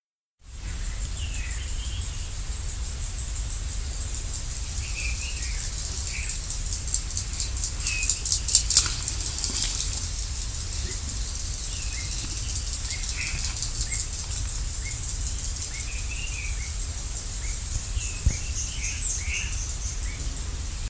Bailarín Oliváceo (Schiffornis virescens)
Nombre en inglés: Greenish Schiffornis
Localidad o área protegida: Reserva Privada y Ecolodge Surucuá
Condición: Silvestre
Certeza: Vocalización Grabada
Bailarin-Olivaceo.mp3